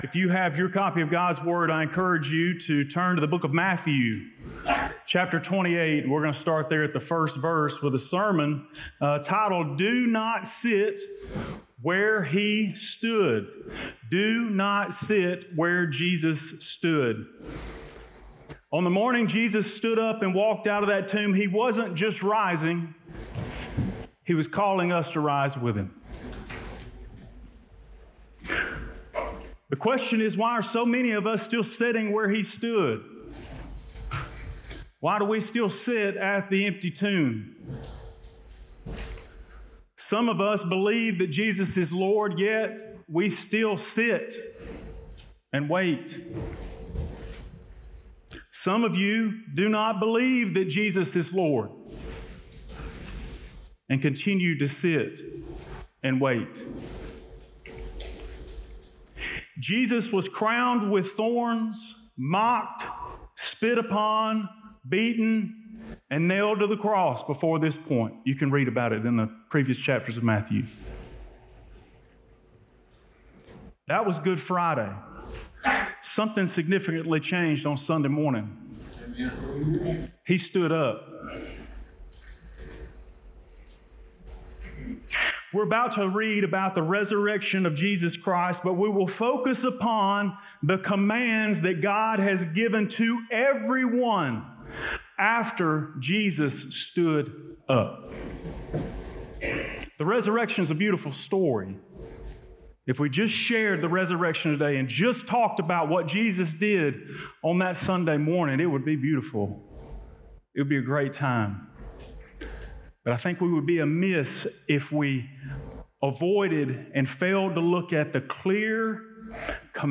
Today's sermon uncovers the challenge and expresses ways for you to be successful in pursuing to achieve it.